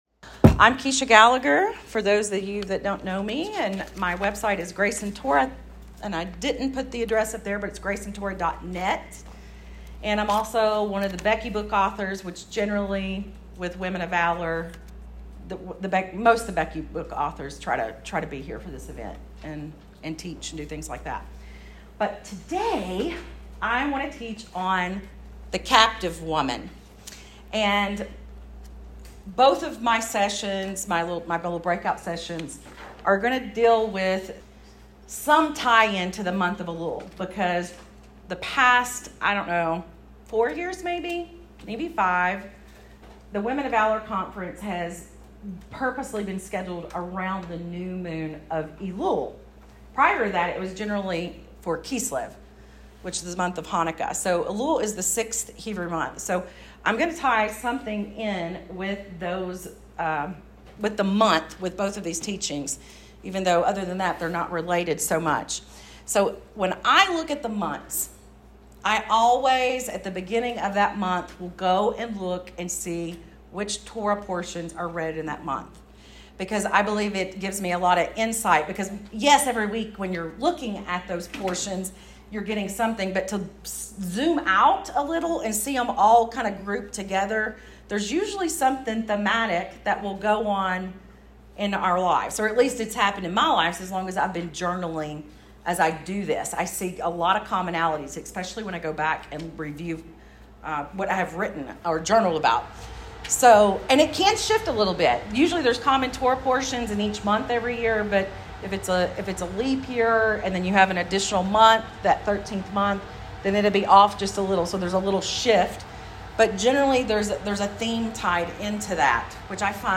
This audio recording is from the recent Women of Valor conference in NC. It is about a segment from this week’s Torah Portion, Ki Tetzei (When you go out).